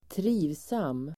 Uttal: [²tr'i:vsam:]